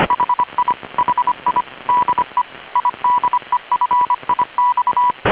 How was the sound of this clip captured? Voice messages Wav file, IDEFIX VHF side very clear S7 CW ID (44Kb)